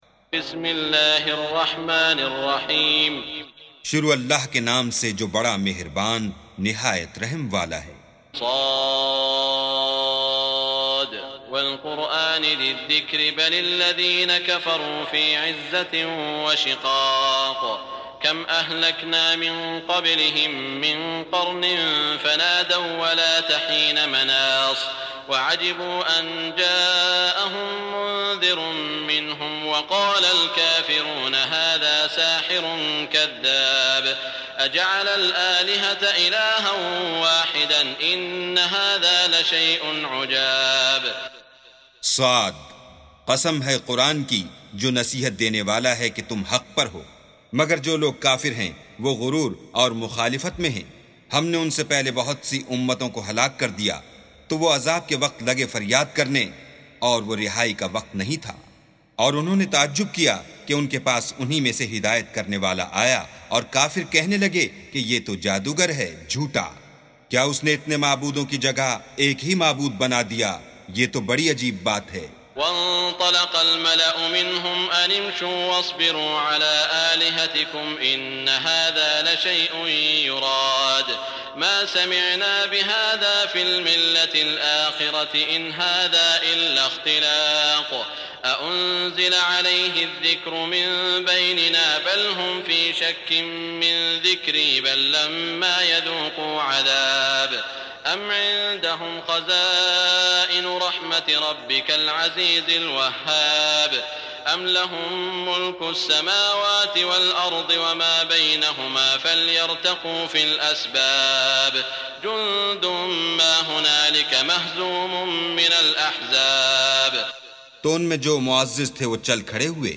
سُورَةُ ص بصوت الشيخ السديس والشريم مترجم إلى الاردو